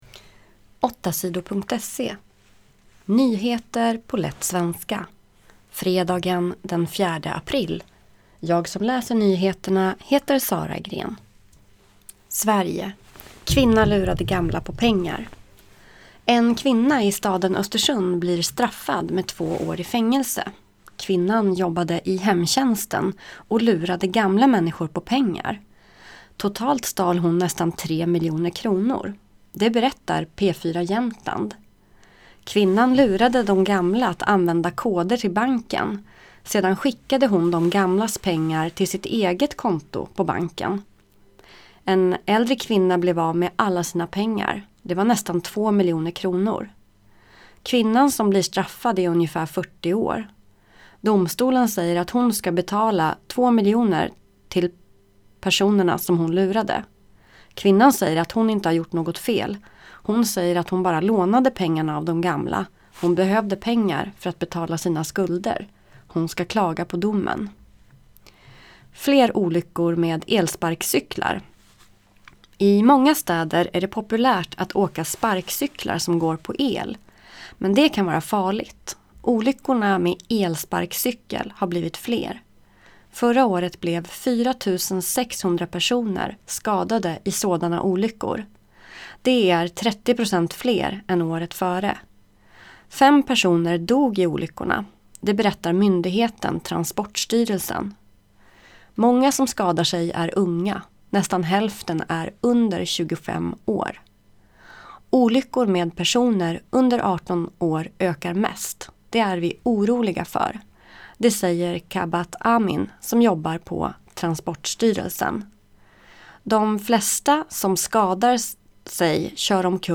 - lättlästa nyheter … continue reading 32 episode # Lyssna 8 Sidor # News Talk # Nyheter # 8 Sidor